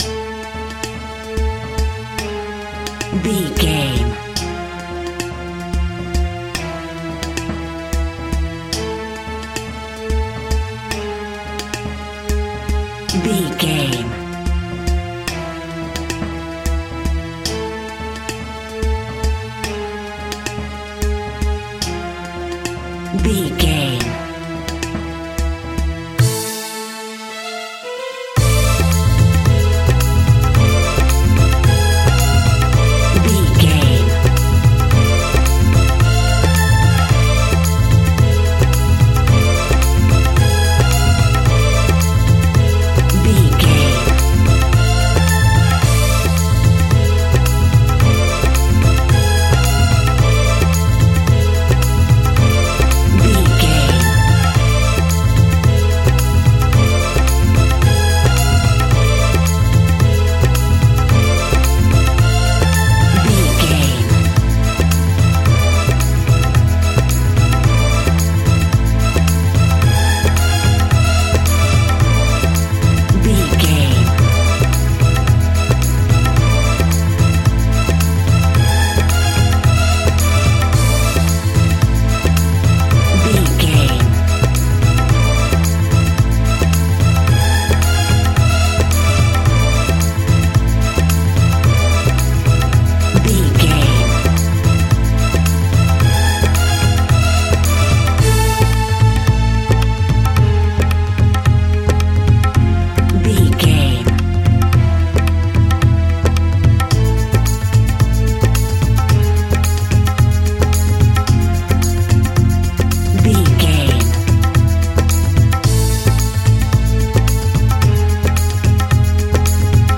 Uplifting
Aeolian/Minor
World Music
percussion